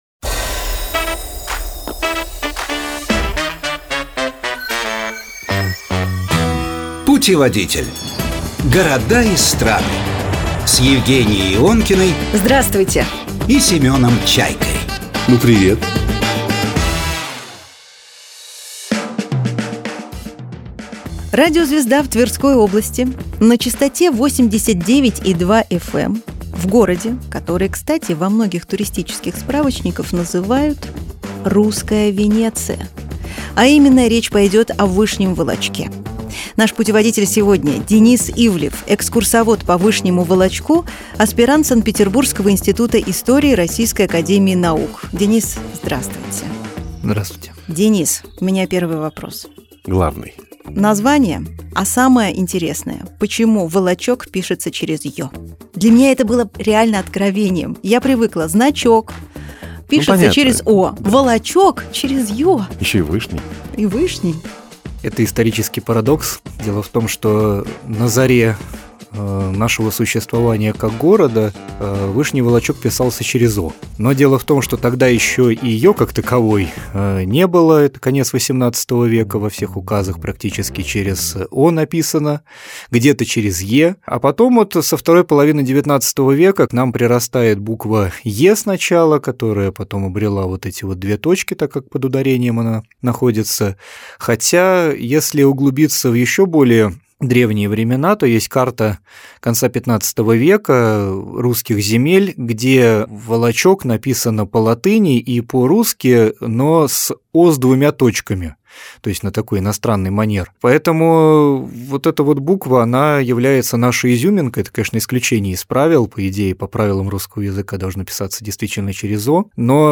В студии программы «Путеводитель»